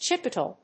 /tʃɪˈpəʉt.leɪ(米国英語), tʃɪˈpəʊˌtli:(英国英語)/